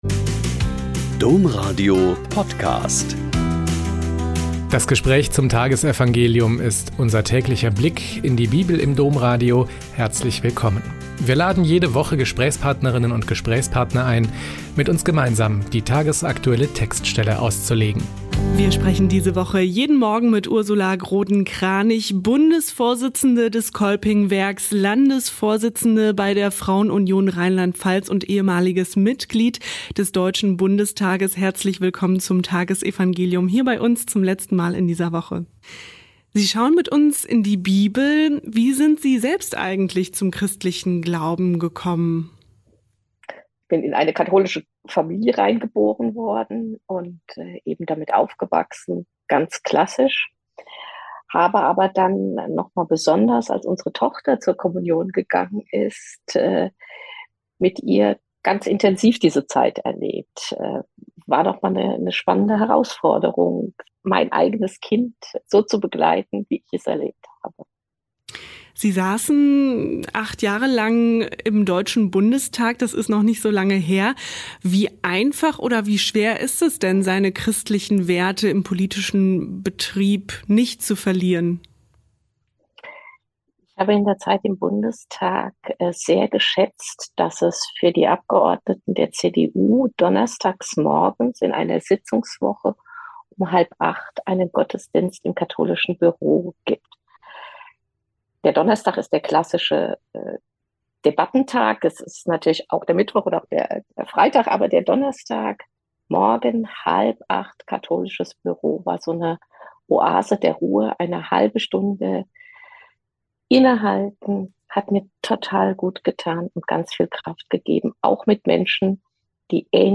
Joh 21,20-25 - Gespräch mit Ursula Groden-Kranich